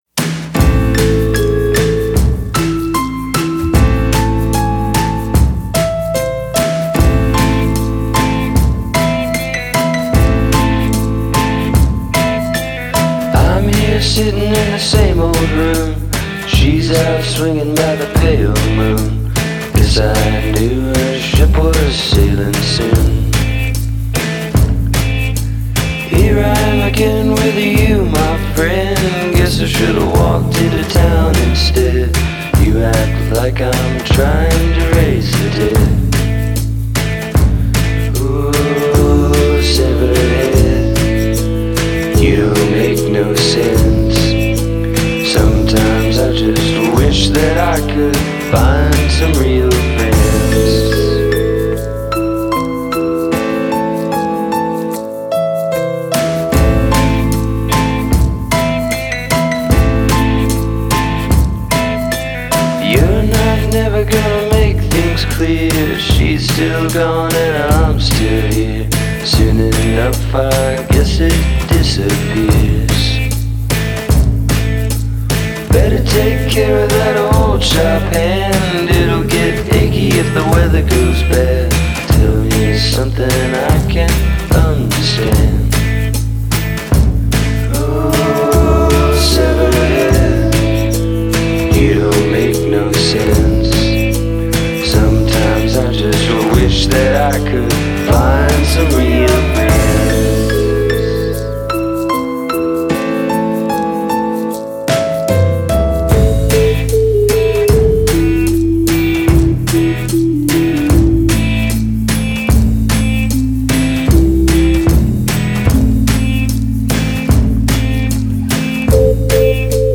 Oh Severed Head” on the guitar, with an interlude on the kazoo.